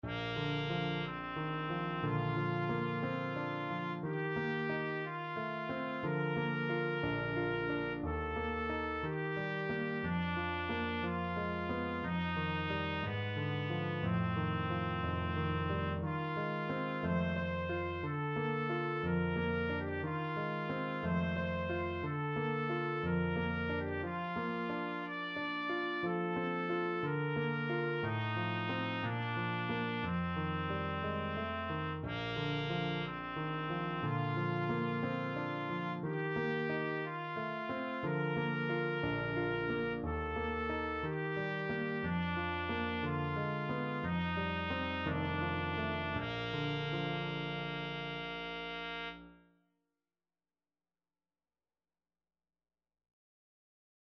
Trumpet
Bb major (Sounding Pitch) C major (Trumpet in Bb) (View more Bb major Music for Trumpet )
~ = 60 Andantino (View more music marked Andantino)
2/4 (View more 2/4 Music)
Bb4-D6
Classical (View more Classical Trumpet Music)